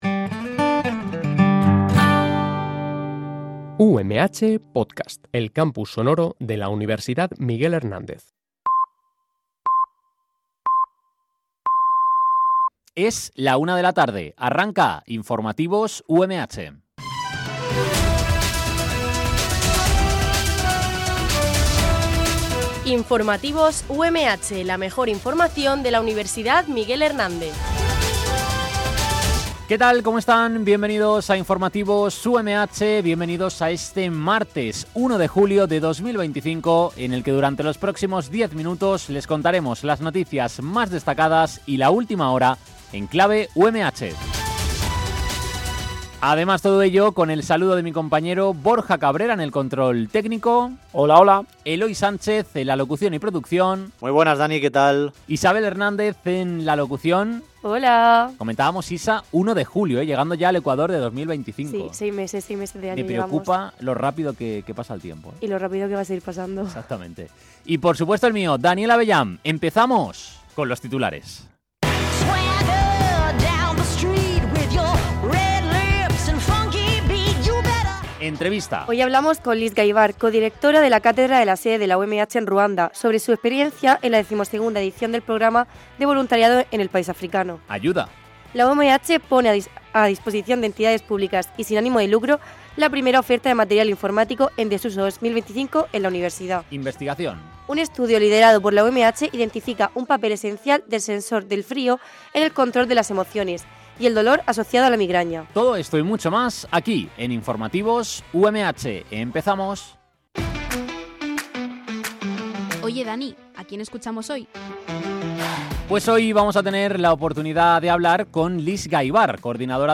– Un estudio liderado por la UMH identifica un papel esencial del sensor del frío en el control de las emociones y el dolor asociado a la migraña. Este programa de noticias se emite de lunes a viernes